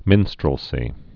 (mĭnstrəl-sē)